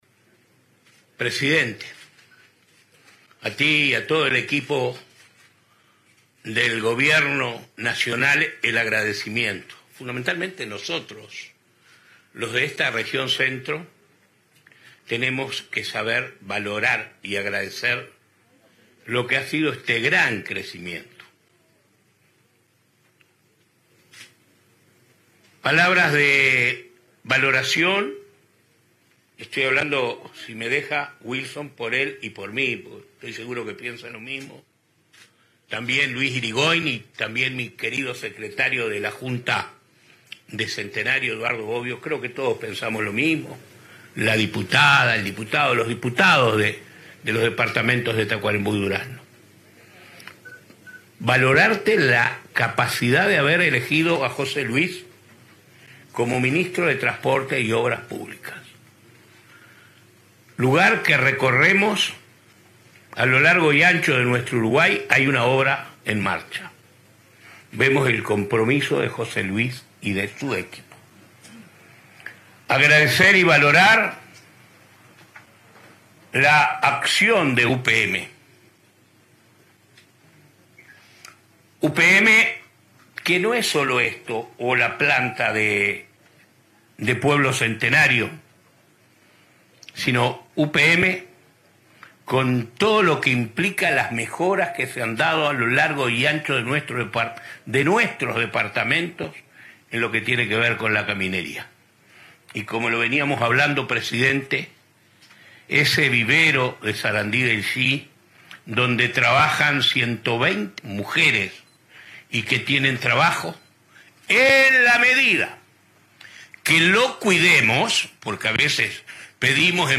Declaraciones de prensa por la inauguración del puente entre Centenario y Paso de los Toros
Declaraciones de prensa por la inauguración del puente entre Centenario y Paso de los Toros 30/01/2023 Compartir Facebook Twitter Copiar enlace WhatsApp LinkedIn En el marco de la ceremonia de inauguración del puente Centenario, entre la localidad homónima y Paso de los Toros, este 30 de enero, el ministro de Transporte y Obras Públicas, José Luis Falero, y el intendente de Durazno, Carmelo Vidalín, realizaron declaraciones.